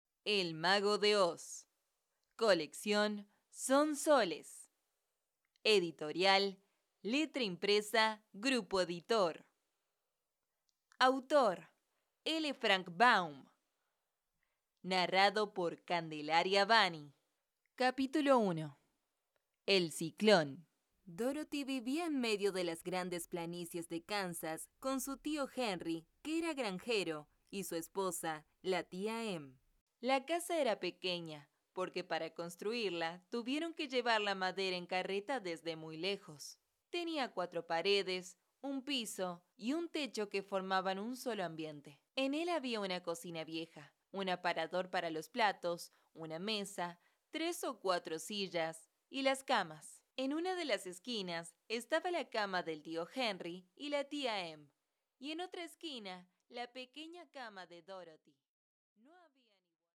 Muestra de audiolibro